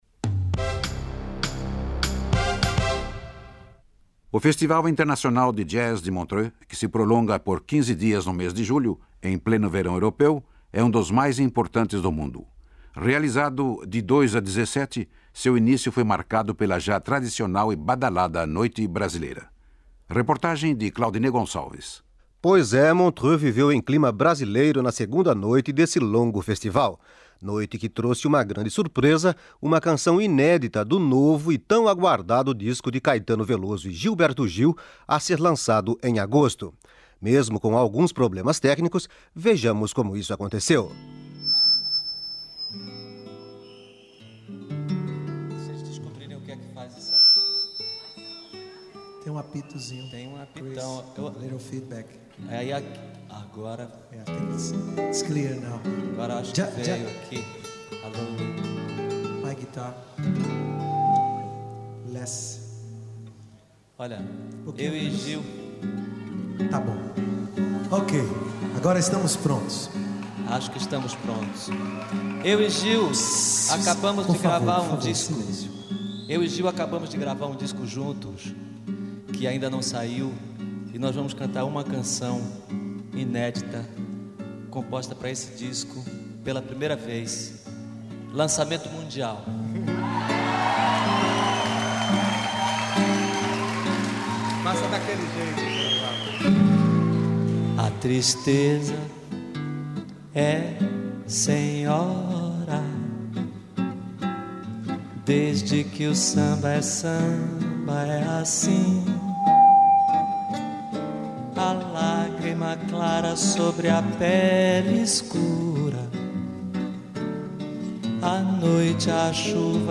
"Lançamento mundial" de canção de Caetano e Gil em Montreux. Entrevista.
Arquivos da Rádio Suíça Internacional, com colaboração de Memoriav.